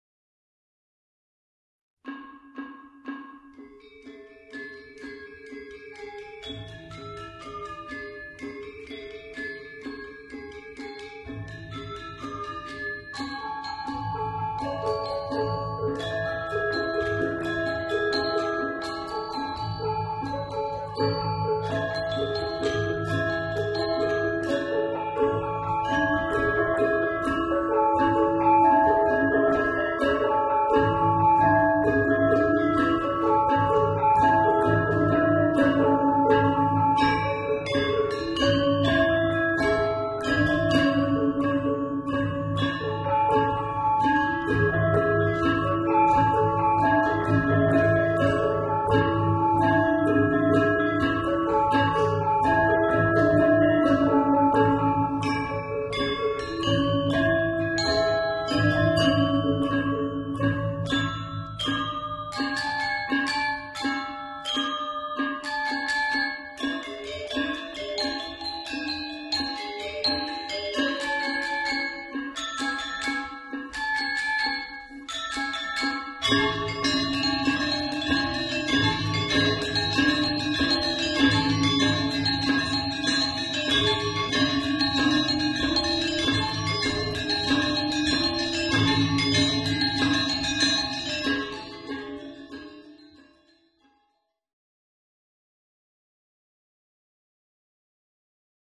for Balinese Gamelan